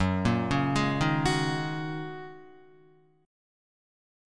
F#6add9 chord